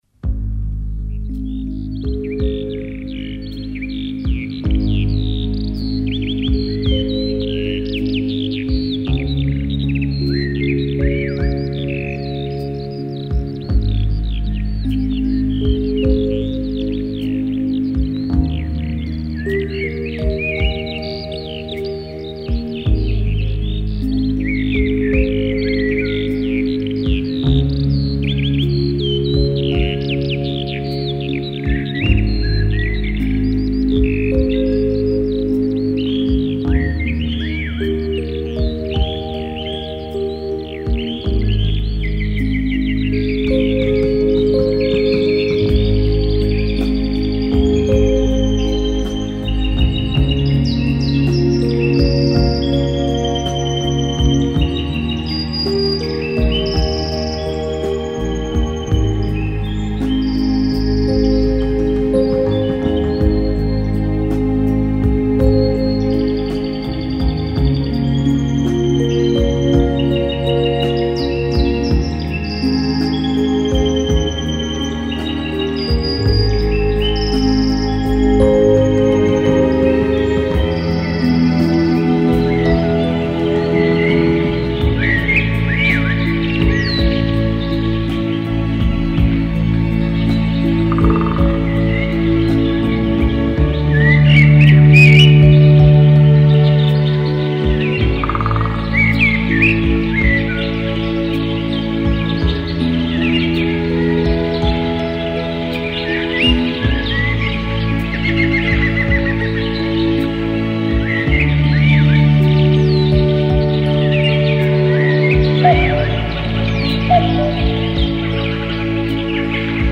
[6/1/2010]融入自然、清新养神 聆听一曲轻音 激动社区，陪你一起慢慢变老！